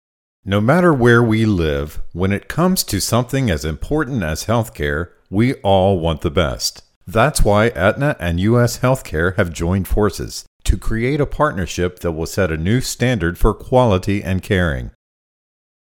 ROOM REFLECTION:
This file is an example of how a poorly treated audio space reflects waveforms back into the microphone and give the sound a bit of reverb or echo. It sounds like he is using an OK quality condenser microphone, but the reflections make this pretty much unusable by a top client.
This file also contains a few plosives.